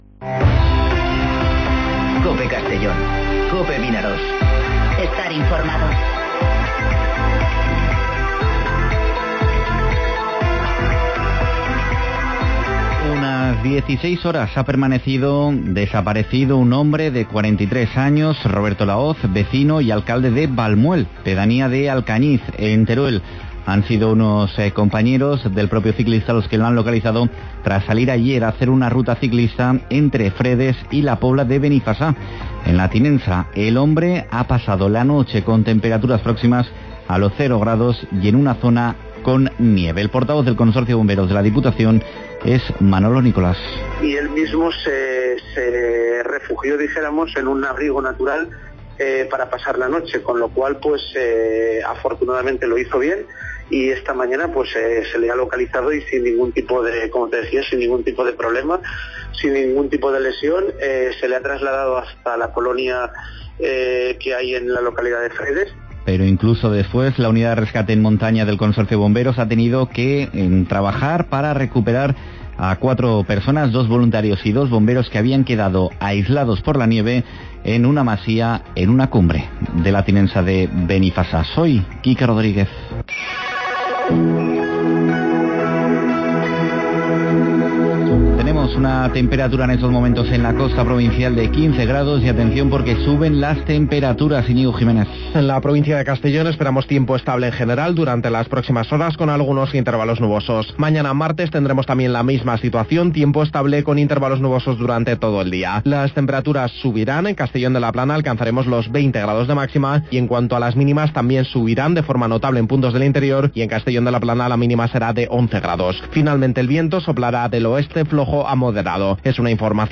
Informativo Mediodía COPE en Castellón (27/01/2020)